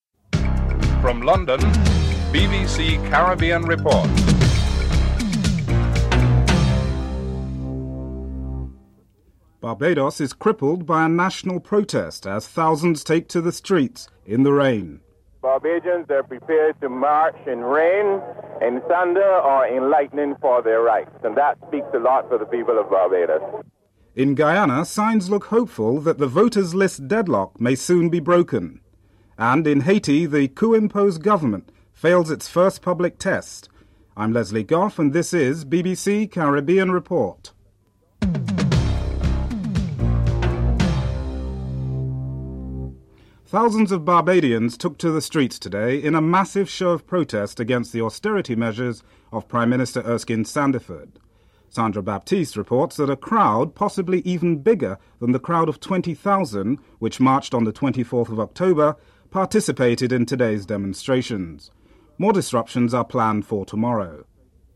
1. Headlines (00:00-00:42)